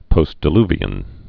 (pōstdĭ-lvē-ən) also post·di·lu·vi·al (-əl) Bible